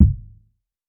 TC Kick 26.wav